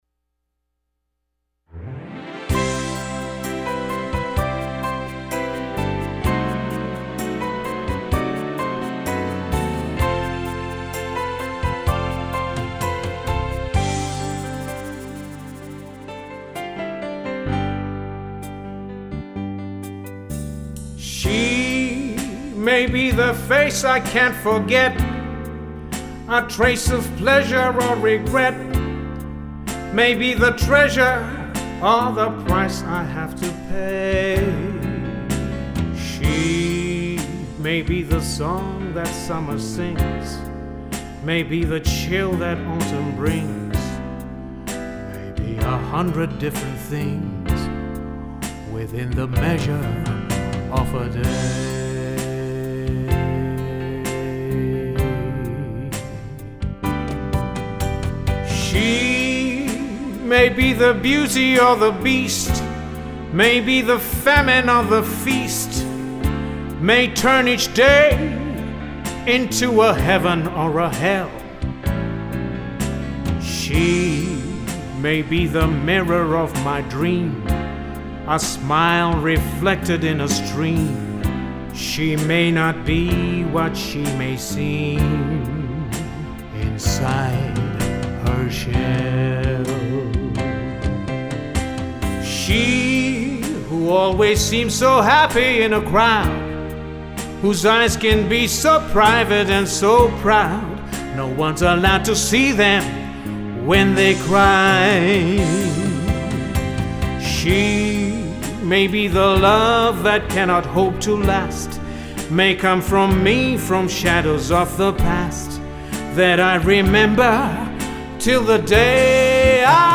Contemporary Pop Ballads